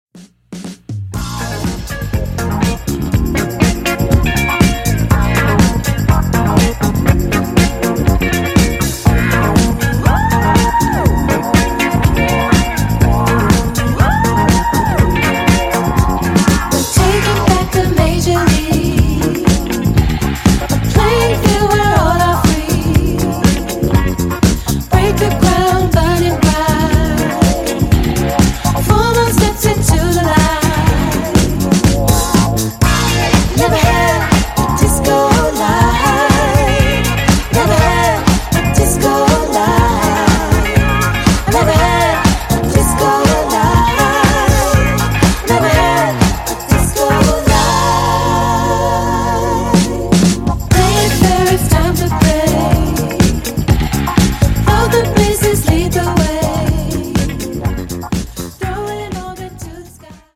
Post punk disco vibes